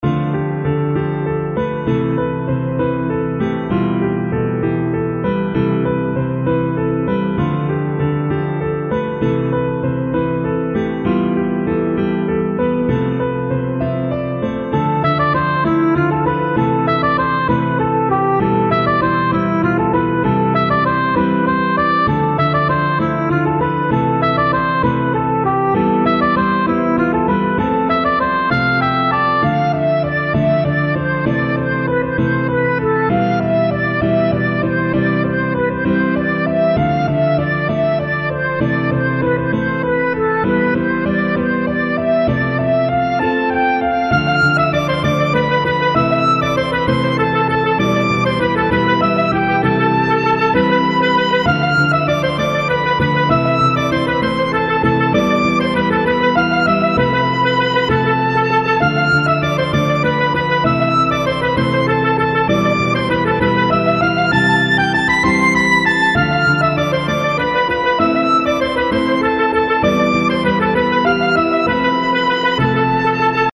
それぞれ１ループの音源です♪
イントロなし